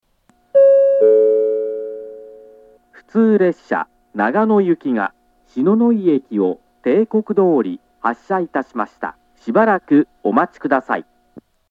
２番線篠ノ井駅発車案内放送 普通長野行の放送です。
列車が篠ノ井駅を発車すると流れます。